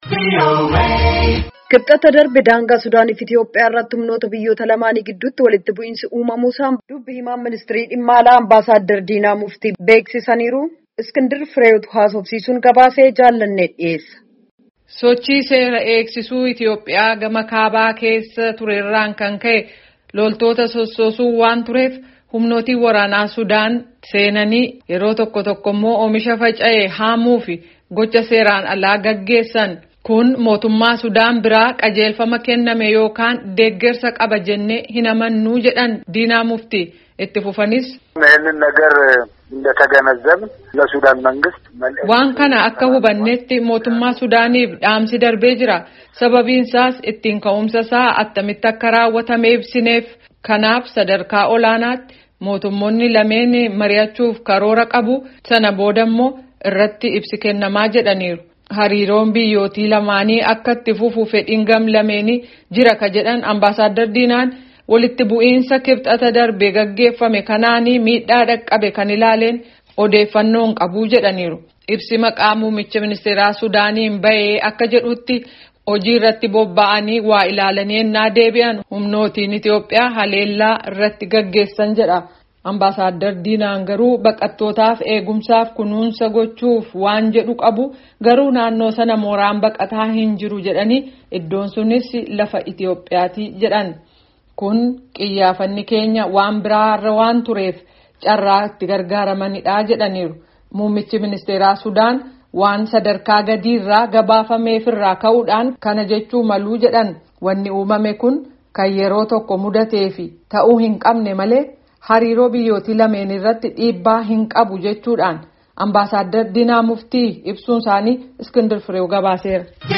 Gabaasa